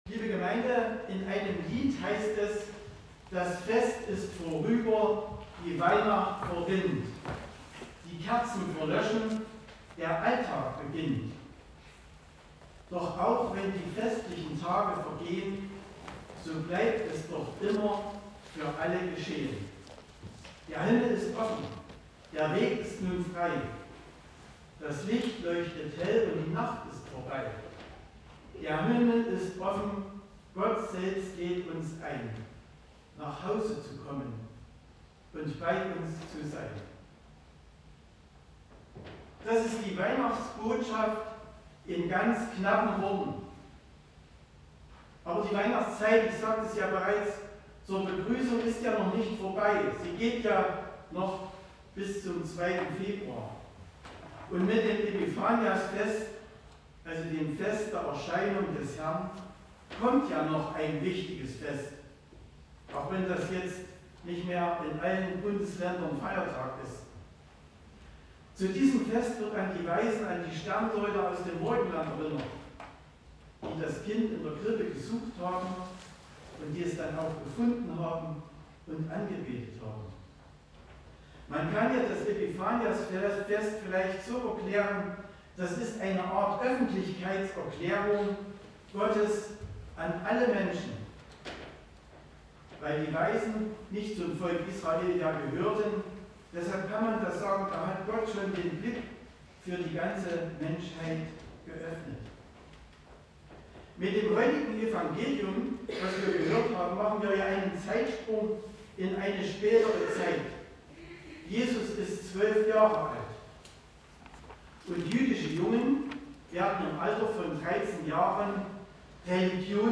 Passage: Lukas 2; 41-52 Gottesdienstart: Gemeinsamer Gottesdienst « Mit JESUS können wir getrost ins NEUE JAHR gehen.